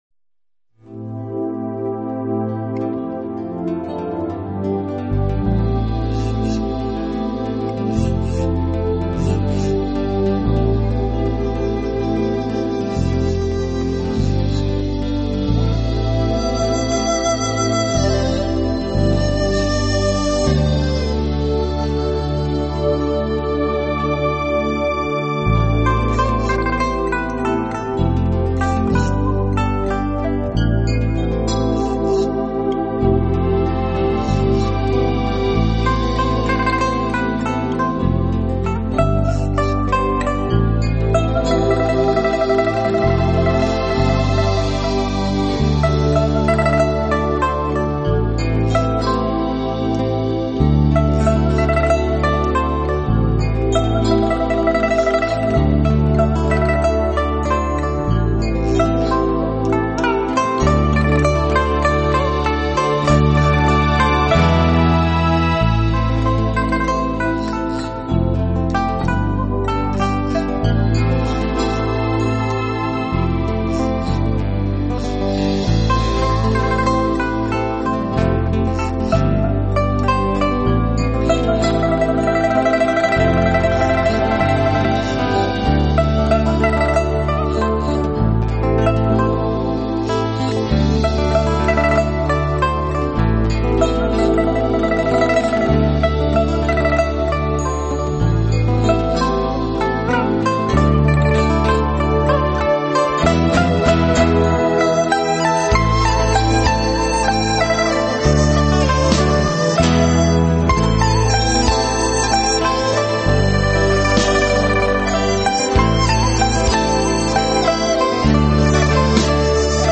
现代佳丽组合的民族音乐跨界远征
古典乐器演绎新民乐曲风绚丽多采